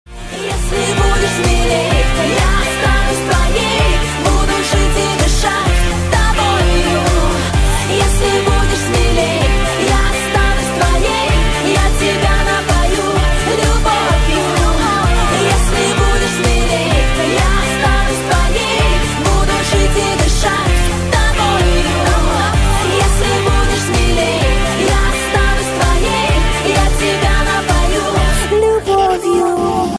поп
громкие
женский вокал